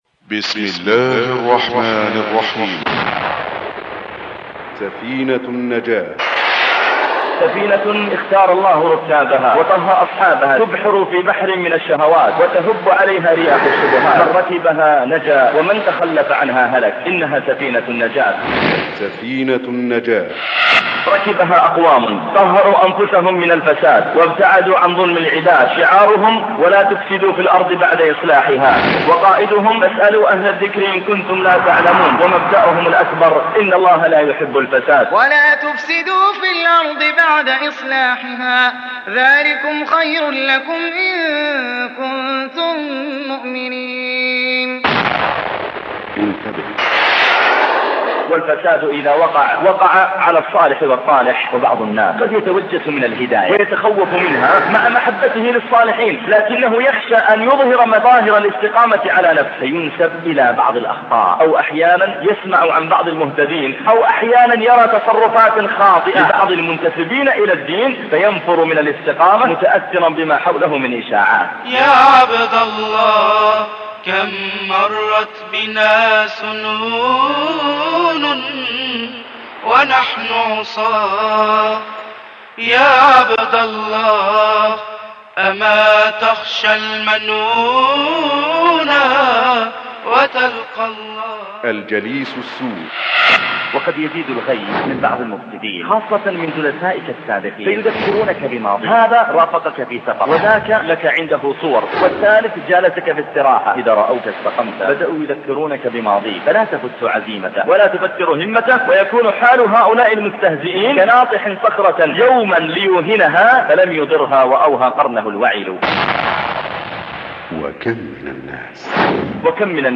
khotab-download-88646.htm